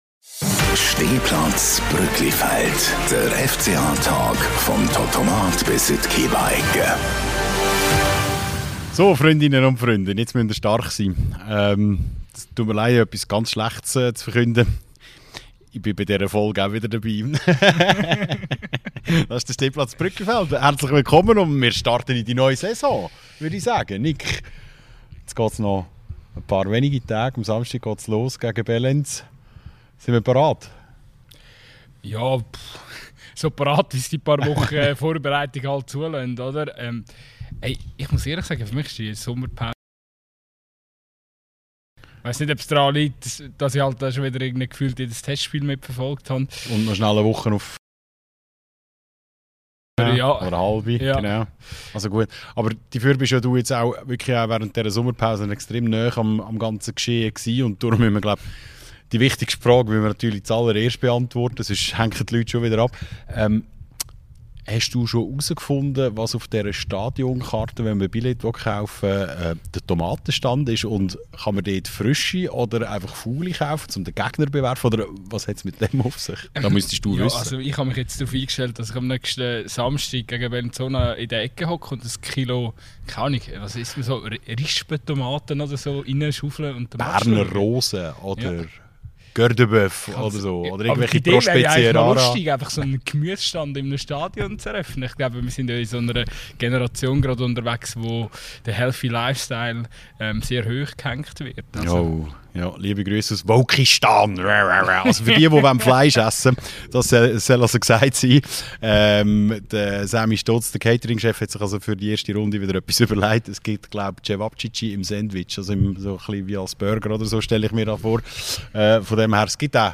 Die neue Saison startet mit dem Heimspiel gegen Bellinzona und wir diskutieren auf dem heiligen Rasen im Brügglifeld die wichtigsten Themen. Transfers, Gegner, das Testspiel gegen ein Team aus Saudi-Arabien - und sogar, von wo aus vegetarische Wurfgeschosse auf den Platz fliegen könnten.